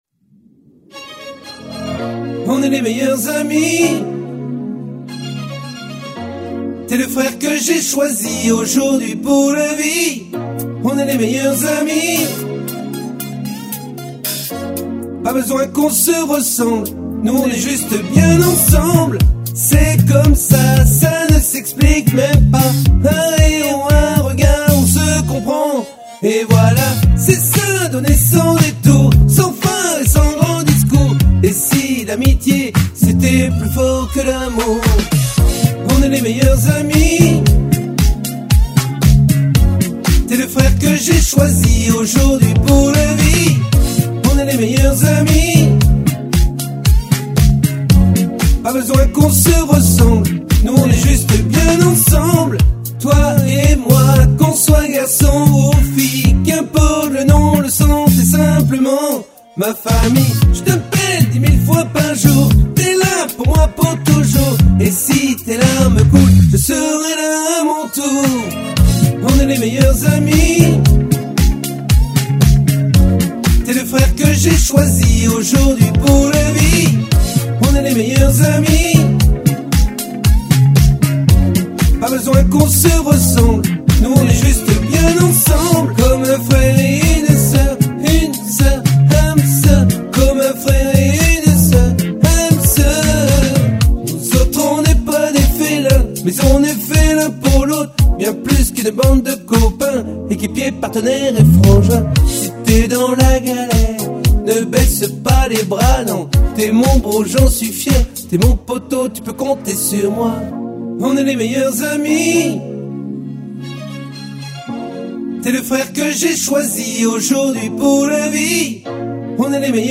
Version enfants (bonne tonalité)